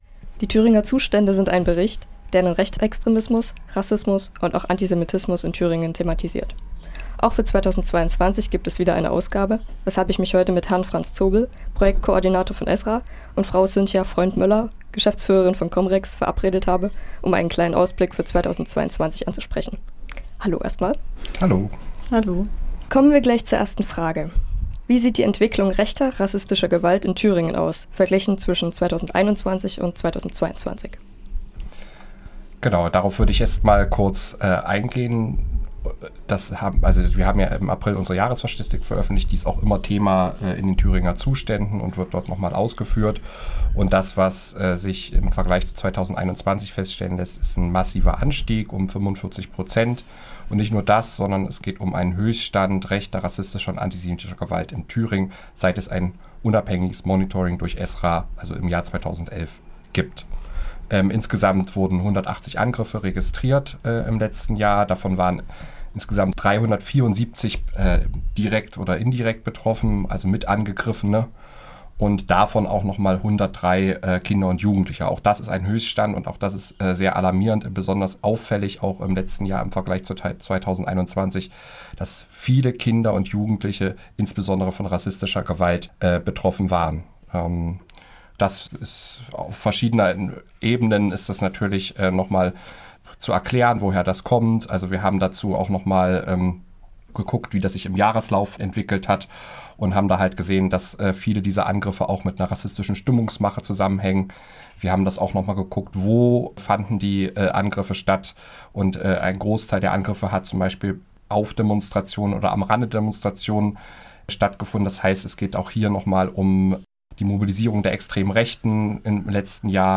Thüringer Zustände 2022 | Interview